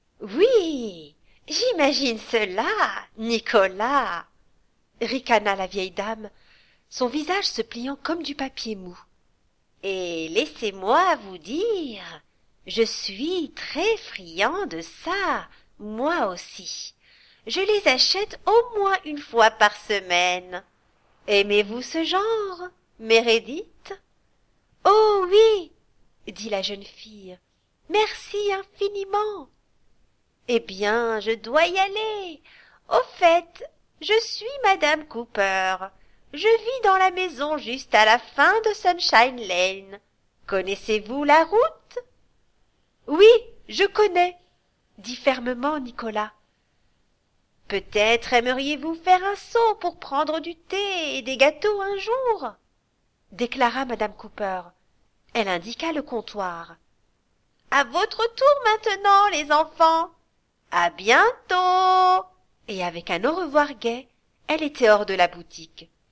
Ma voix est plutôt médium et féminine, l´idéale les spots publicitaires, narration d´histoire pour enfant, présentation vidéo de produits...
Voix off française féminine institutionnelle, calme pour des spots publicitaires ou narration.
Sprechprobe: eLearning (Muttersprache):
French voice over artist with a naturally warm, articulate and engaging voice, specializing in audio books, childrens narration.
I work from my home studio and can normally deliver within 24 hours.